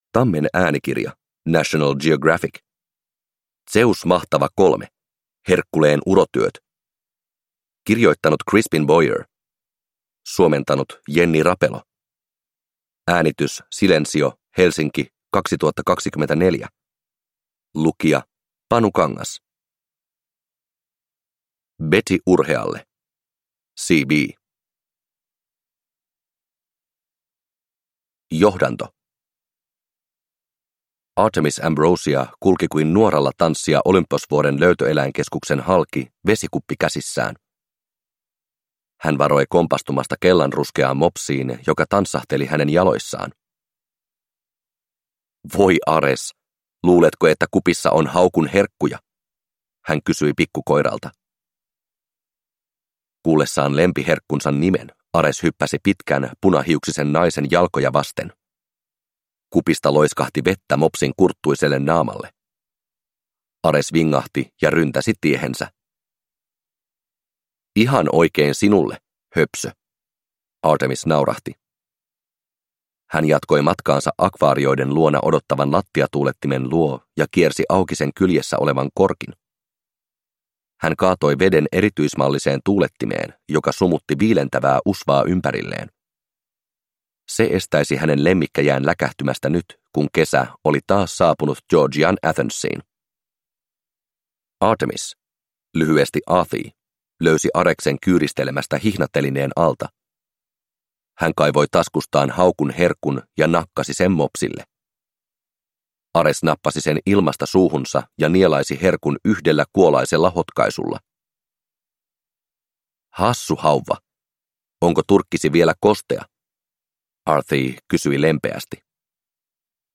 Zeus Mahtava 3. Herkkuleen urotyöt – Ljudbok